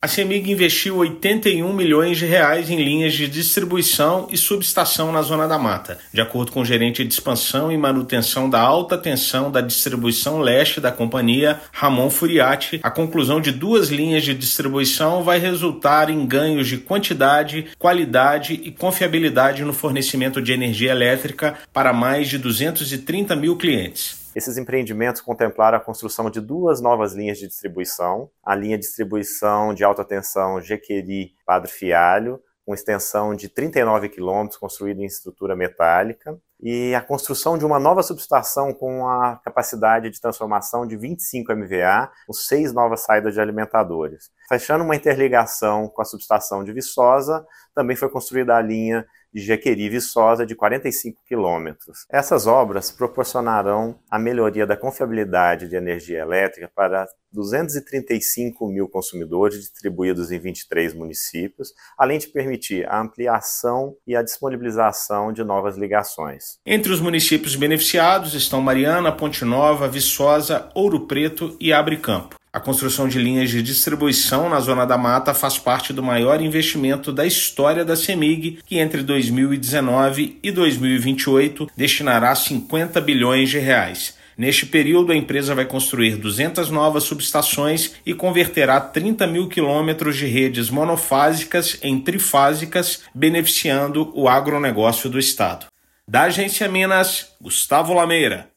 [RÁDIO] Cemig investe R$ 81 milhões em linhas de distribuição e subestação na Zona da Mata e beneficia mais de meio milhão de pessoas
Ao todo, 29 municípios da região serão atendidos pelo novo empreendimento da companhia. Ouça matéria de rádio.